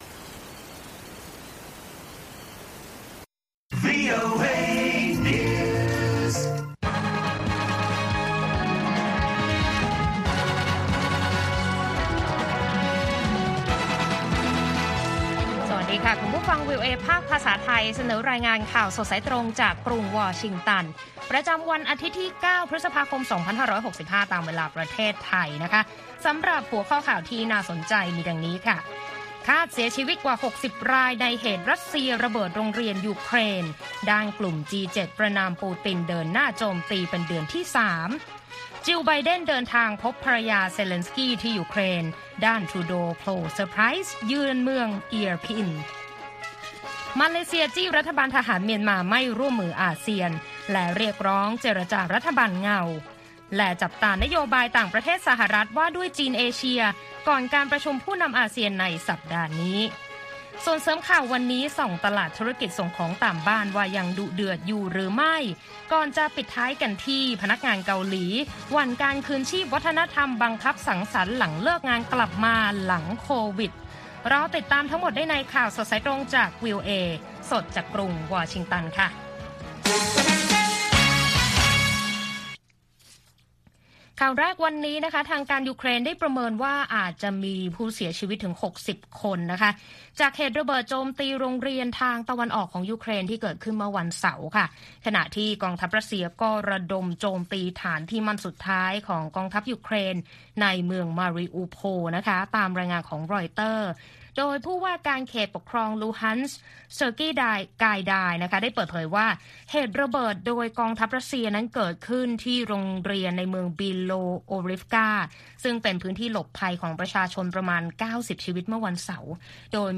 ข่าวสดสายตรงจากวีโอเอไทย วันที่ 9 พ.ค. 2565